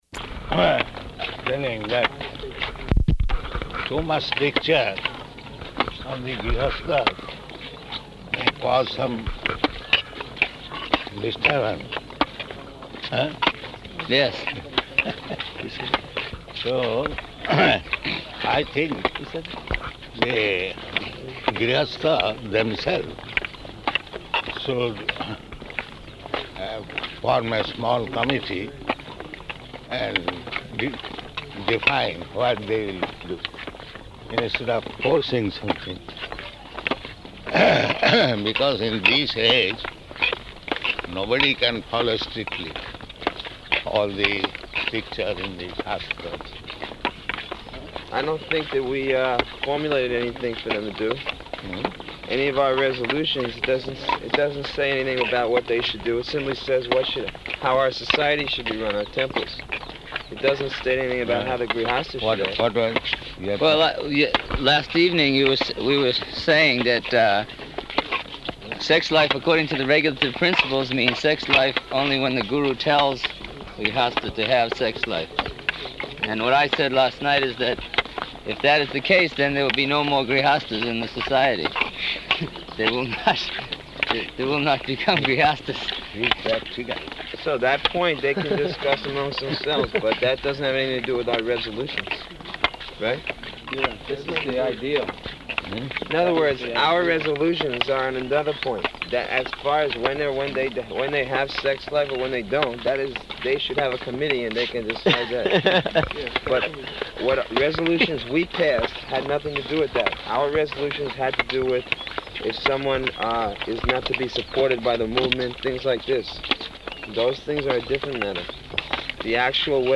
Morning Walk --:-- --:-- Type: Walk Dated: March 10th 1976 Location: Māyāpur Audio file: 760310MW.MAY.mp3 Prabhupāda: ...telling that too much stricture on the gṛhasthas may cause some disturbance.